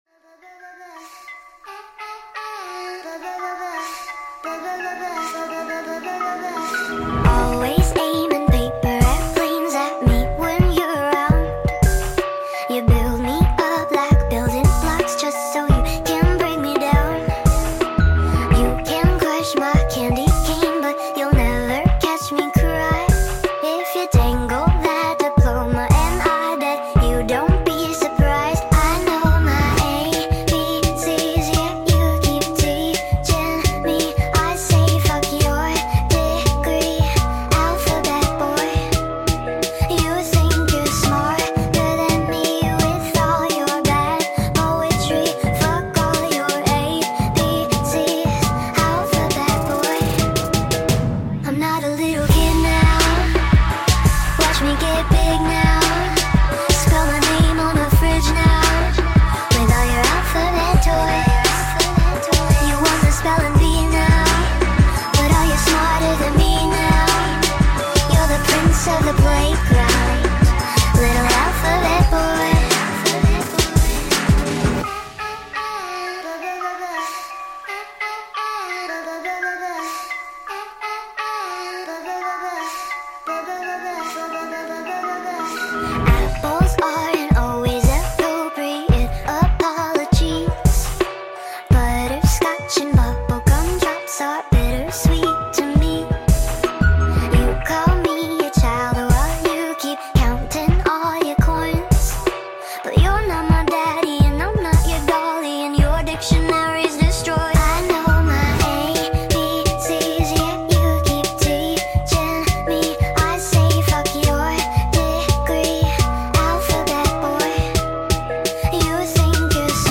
full song sped up